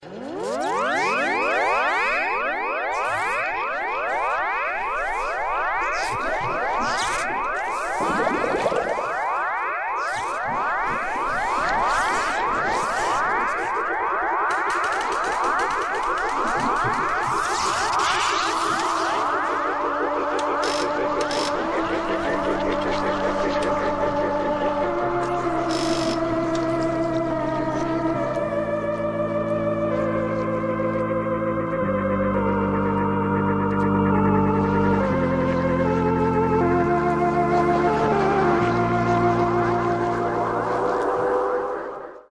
Звук машины времени из кинокартины Иван Васильевич меняет профессию